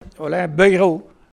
Localisation Olonne-sur-Mer
locutions vernaculaires